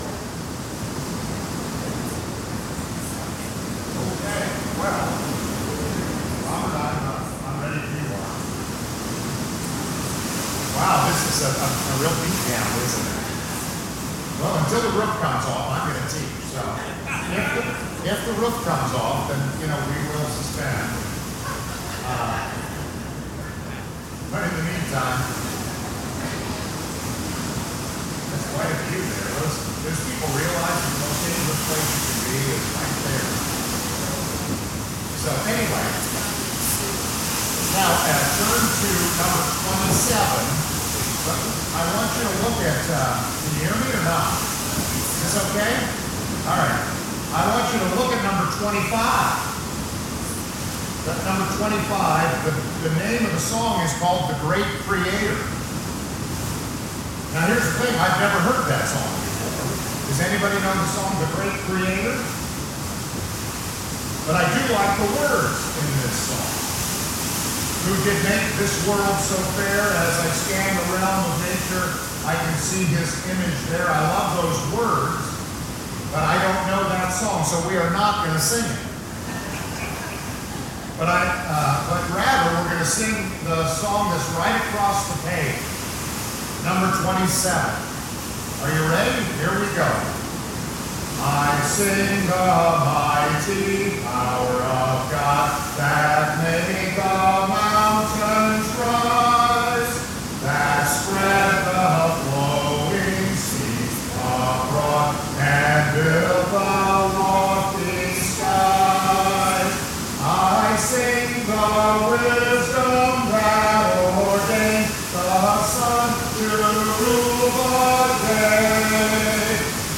We apologize for the background noise during the first 9 minutes of the lesson. A heavy rainstorm was occurring and was very loud in the auditorium.